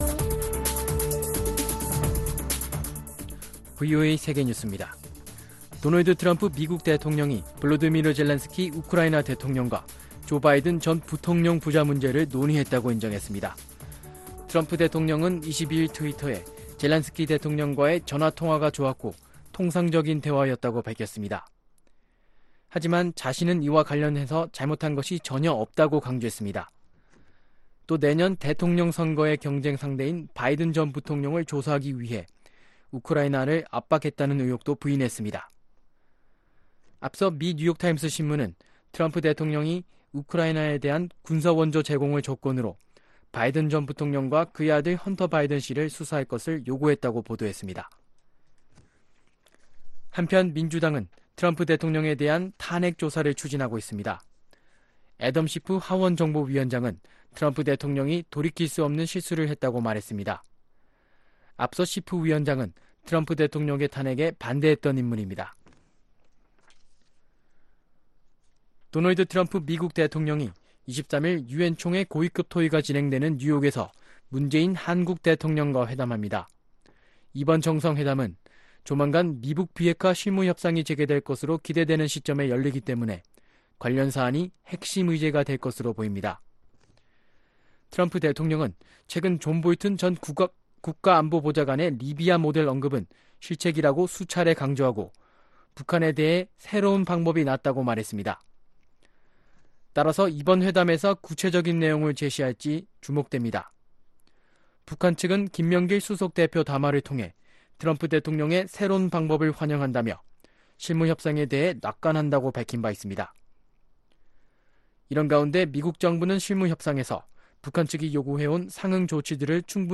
VOA 한국어 아침 뉴스 프로그램 '워싱턴 뉴스 광장' 2019년 9월 24일 방송입니다. 유엔총회에 세번째 참석하는 도널드 트럼프 미국 대통령이 올해는 기조연설에서도 북한을 언급할 지 주목됩니다. 아프리카 세네갈에서 북한 건설노동자들이 외화벌이를 하고 있는 사실이 VOA취재로 확인됐습니다.